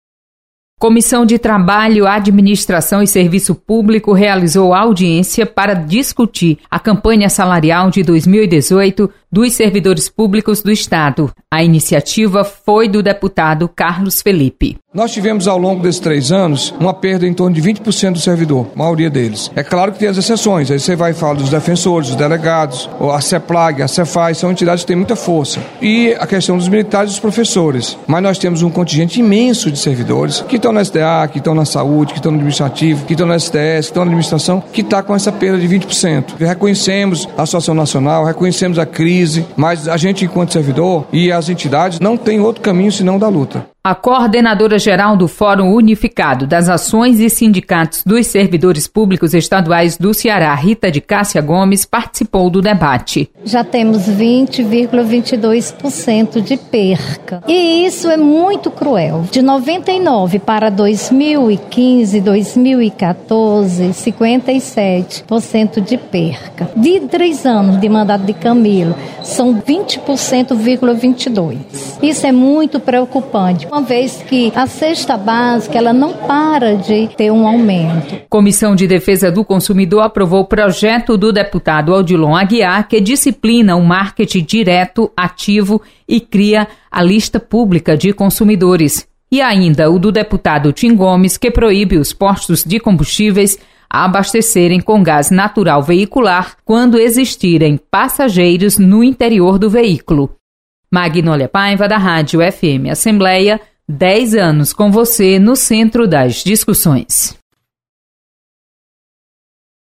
com a repórter